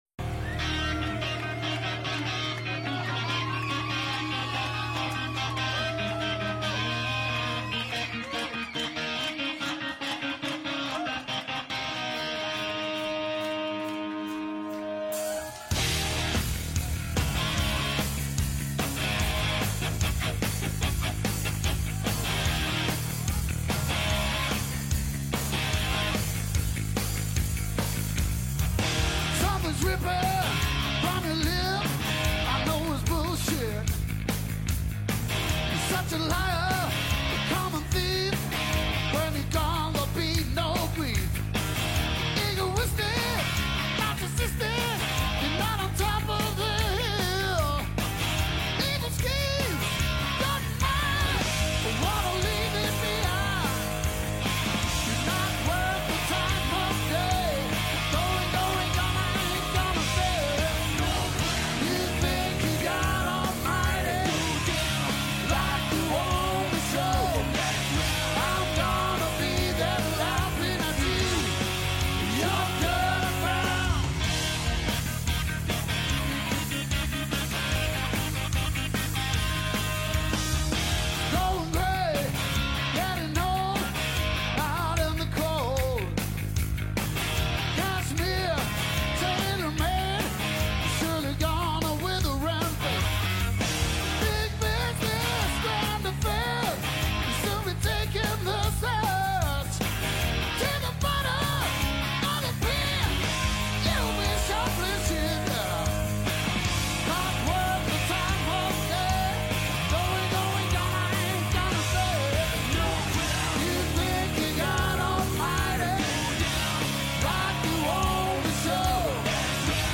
шведський рок-гурт